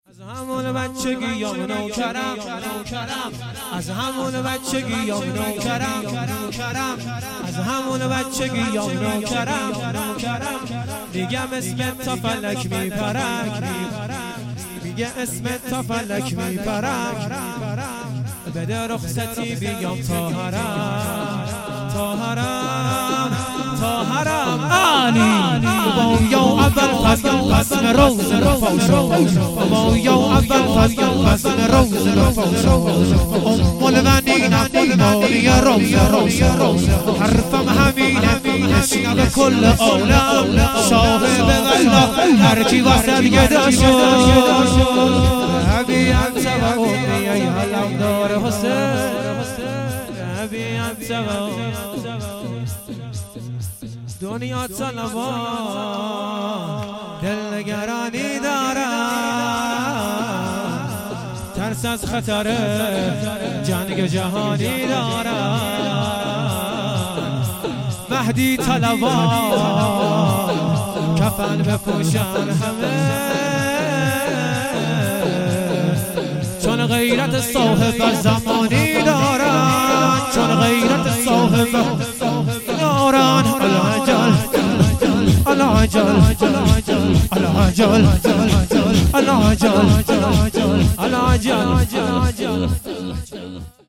هیئت شیفتگان جوادالائمه علیه السلام مشهد الرضا
شور و ذکر
شهادت امام علی ۱۳۹۸